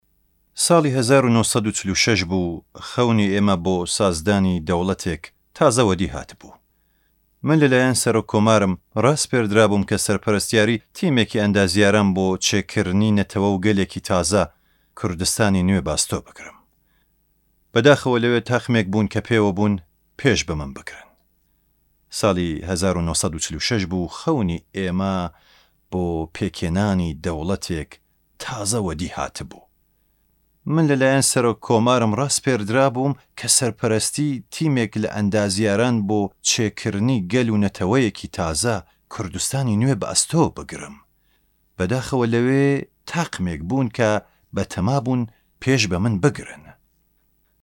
Male
Adult
Documentary